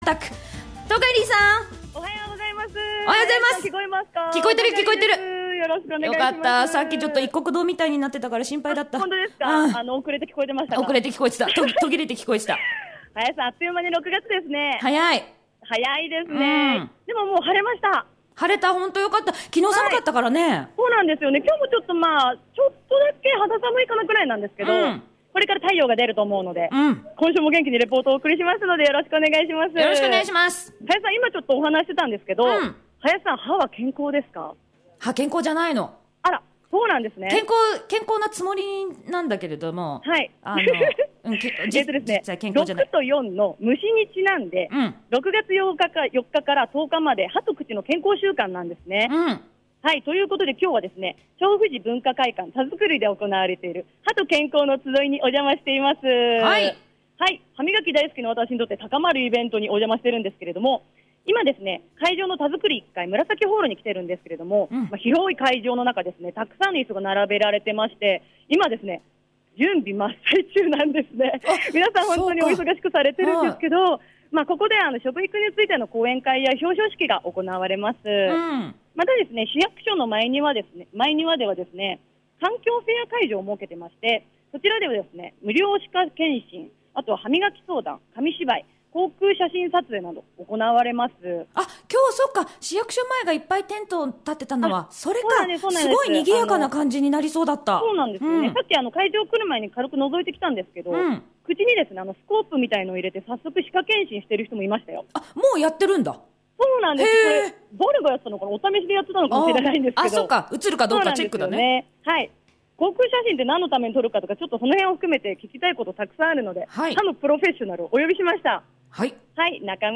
今日は『歯と健康のつどい』にお邪魔してきましたよー。
2015.6.6 レポート 歯と健康のつどい